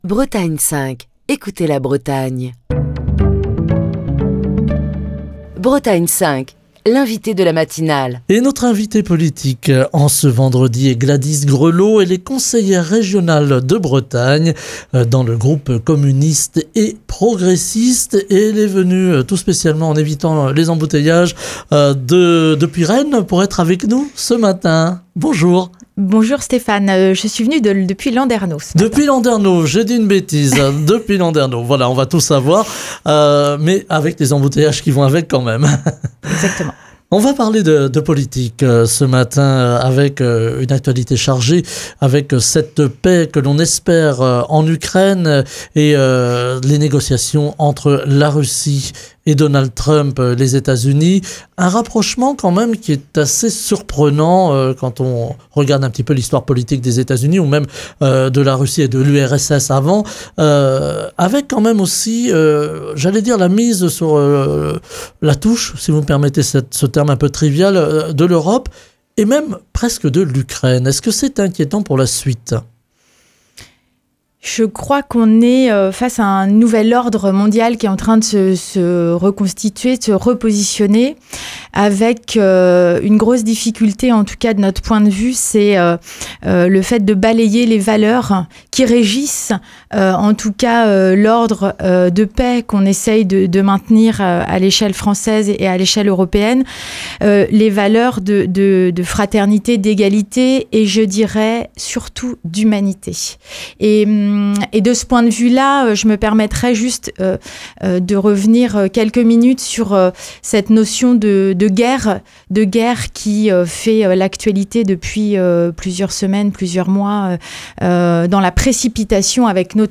Gladys Grelaud, conseillère régionale de Bretagne (Groupe Communistes et Progressistes), était l'invitée politique de la matinale de Bretagne 5, ce vendredi.